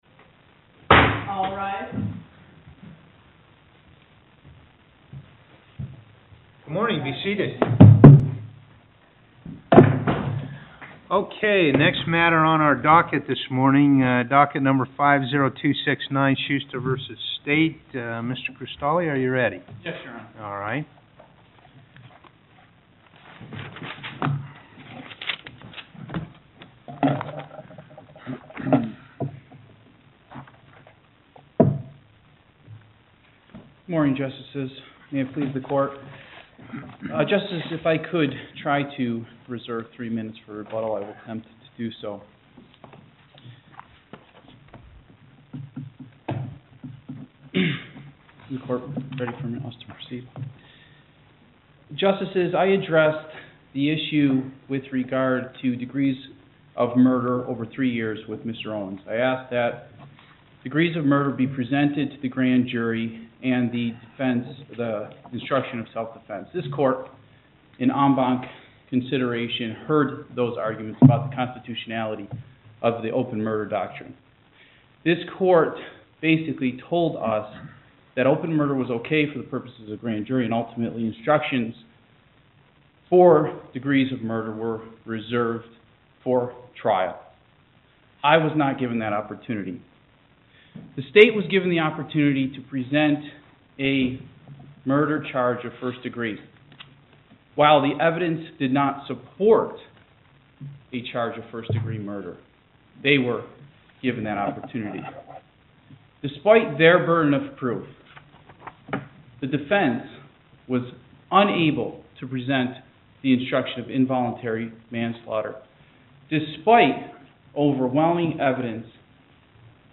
Loading the player Download Recording Docket Number(s): 50269 Date: 10/13/2009 Time: 11:30 am Location: Las Vegas Before the SNP09: Parraguirre/Douglas/Pickering, JJ.